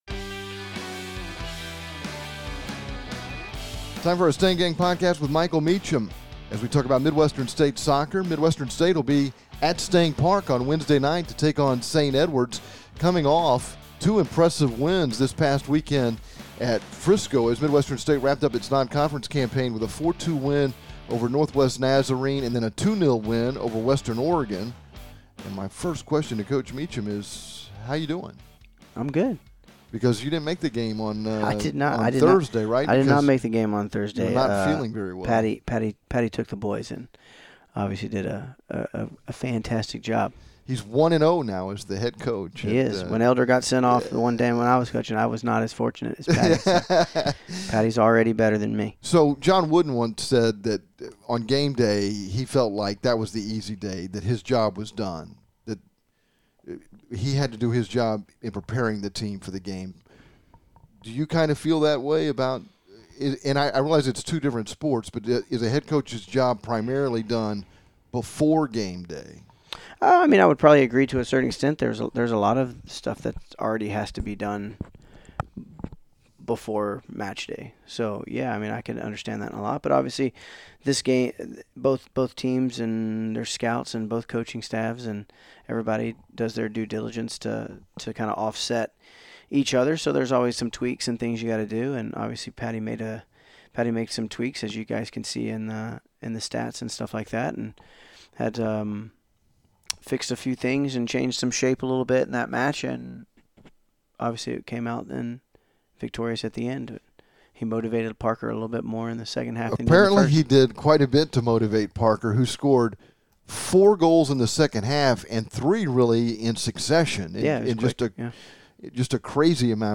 This week's Stang Gang Podcast features complete coverage of Midwestern State Men's Soccer and an interview